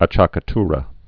(ä-chäkə-trə)